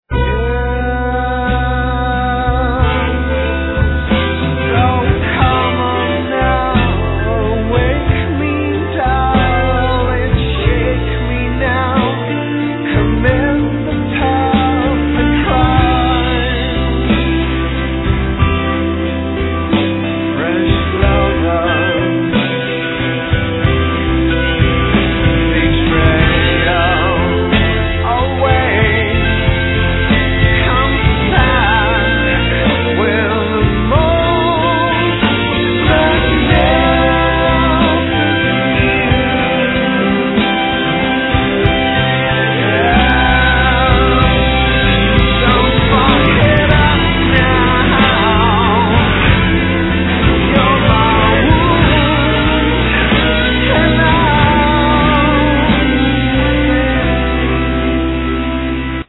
(Lead Vocals, Trumpet, Flugelhorn)
(E-Guitar, Analog Synthesizer, Backing Vocals)
(E-Bass, Double Bass)
(Drums, Backing Vocals)